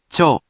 We have our computer friend, QUIZBO™, here to read each of the hiragana aloud to you.
#3.) Which hiragana youon do you hear? Hint: 【cho】
In romaji, 「ちょ」 is transliterated as 「cho」which sounds sort of like 「choh」or the surname「Cho」.